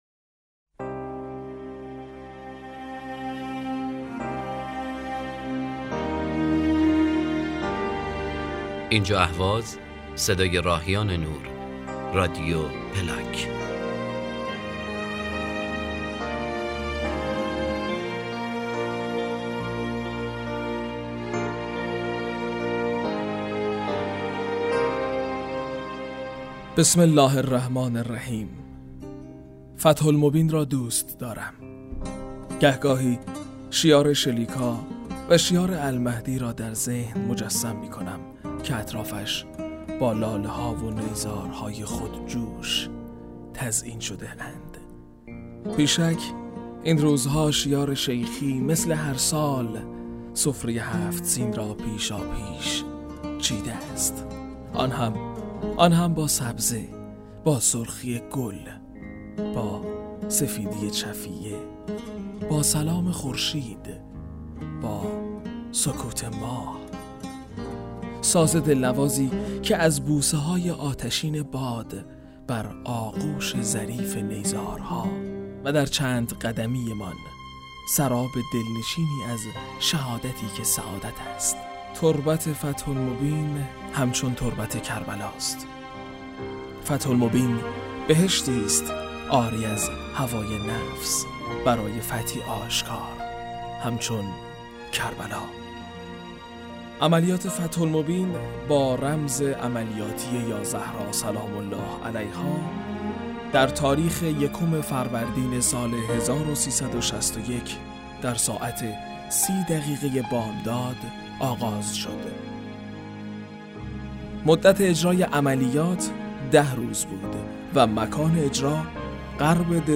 بیان احساسی در خصوص یادمان و شرح عملیات فتح المبین